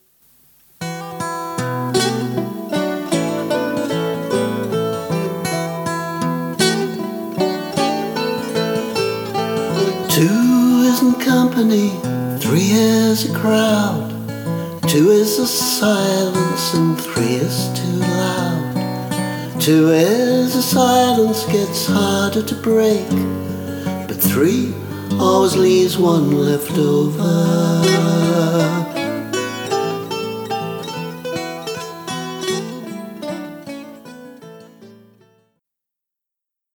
Two is a silence – extract with overdubbed bouzouki